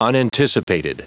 Prononciation du mot : unanticipated